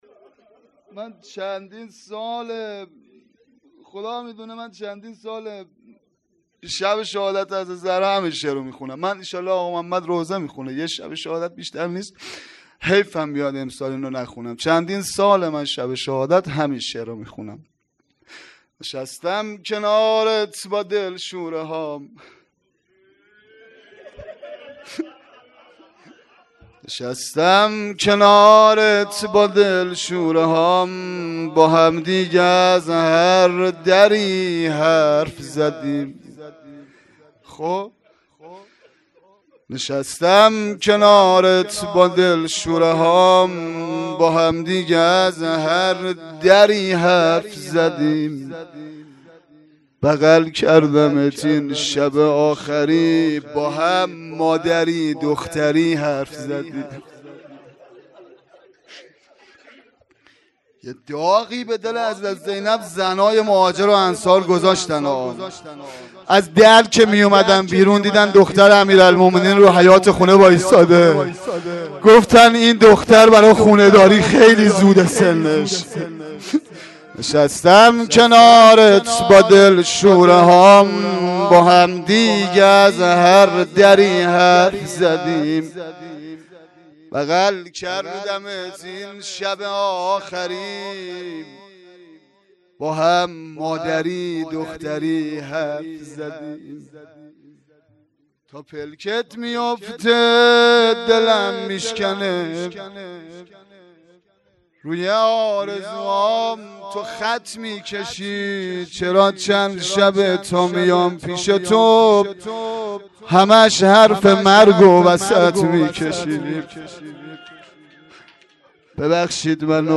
مراسم شهادت حضرت زهرا سلام الله علیها فاطمیه دوم ۱۴۰۳